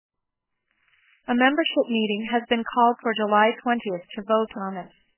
以下是含噪场景中，说话人提取效果的示例。从频谱上看，噪声基本已被去除，提取的语音和原始干净语音对比无明显的失真。